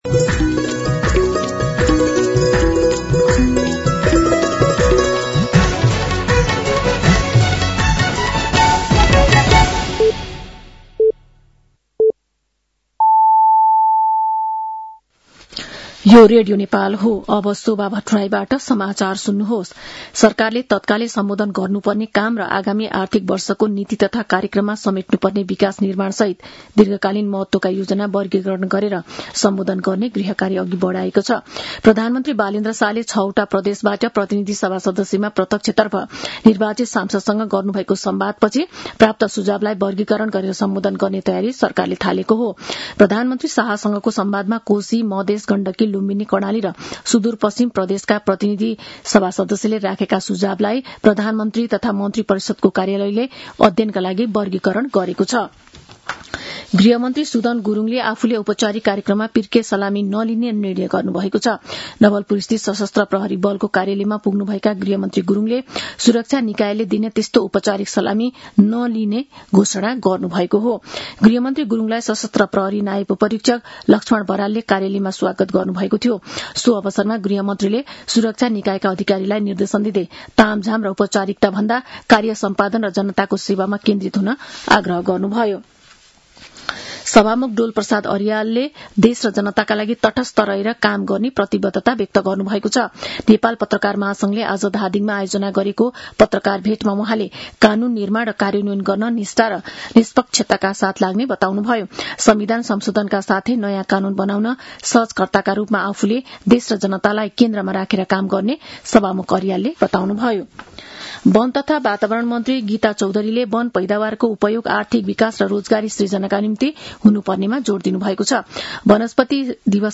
साँझ ५ बजेको नेपाली समाचार : २९ चैत , २०८२
5-pm-news-12-29.mp3